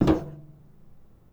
grunk / assets / sfx / footsteps / metal / metal8.wav
metal8.wav